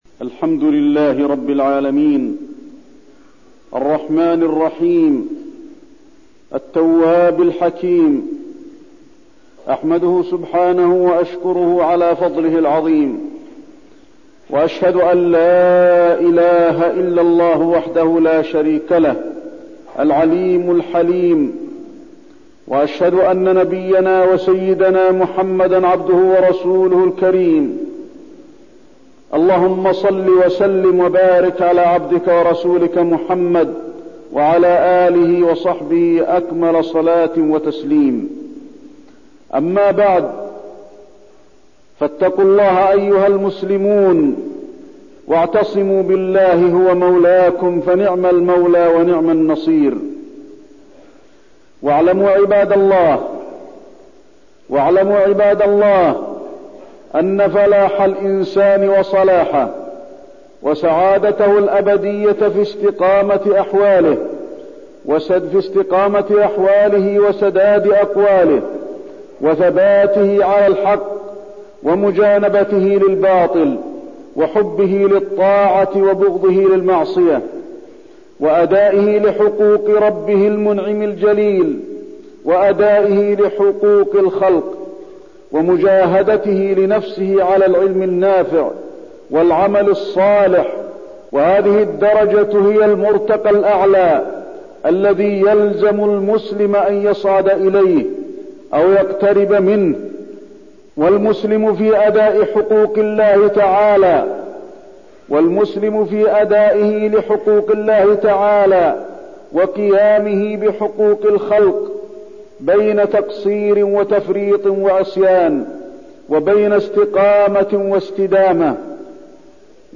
تاريخ النشر ٢٥ جمادى الآخرة ١٤١١ هـ المكان: المسجد النبوي الشيخ: فضيلة الشيخ د. علي بن عبدالرحمن الحذيفي فضيلة الشيخ د. علي بن عبدالرحمن الحذيفي التوبة